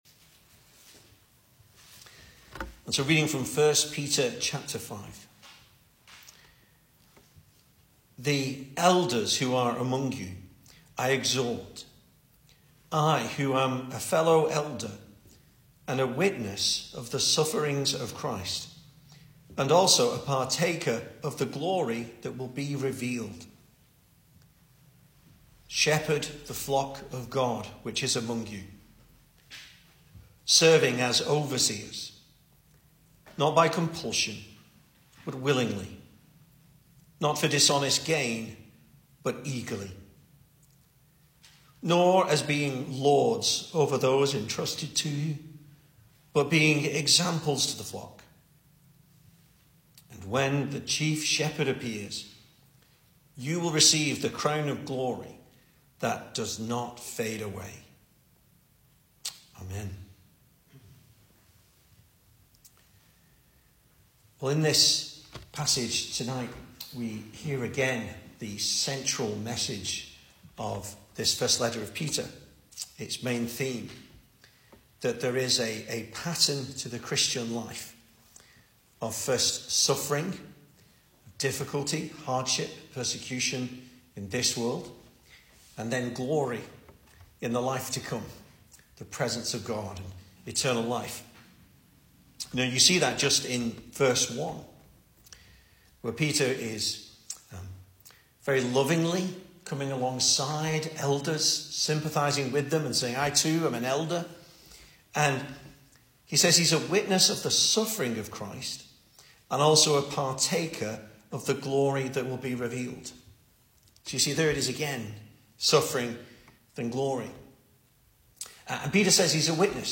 2024 Service Type: Weekday Evening Speaker